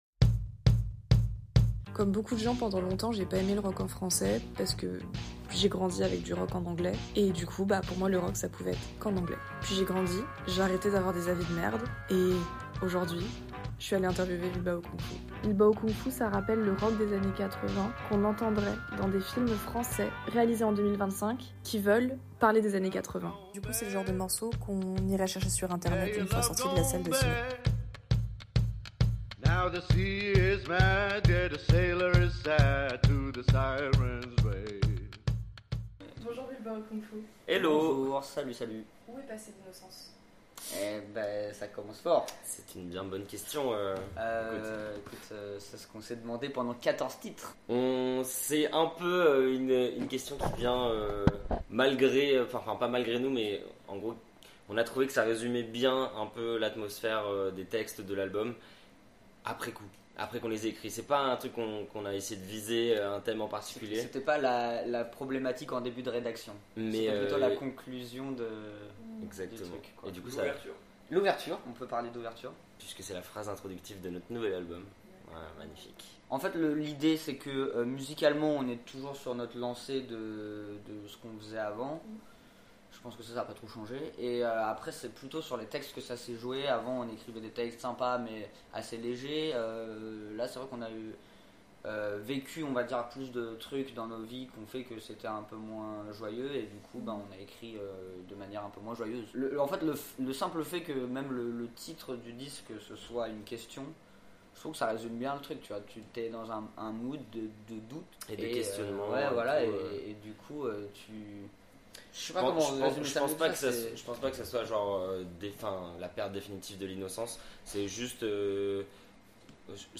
Venus tout droit de Bordeaux, le trio de Bilbao Kung-Fu venait célébrer avec nous, parisien·ne·s, la sortie de leur premier album Où Est Passée L'Innocence ? Évidemment, on s'est demandé où elle est vraiment passée, on a parlé ninjas et on a crié en ninja.
Entretien